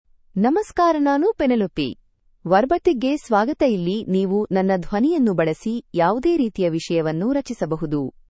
PenelopeFemale Kannada AI voice
Penelope is a female AI voice for Kannada (India).
Voice sample
Listen to Penelope's female Kannada voice.
Female
Penelope delivers clear pronunciation with authentic India Kannada intonation, making your content sound professionally produced.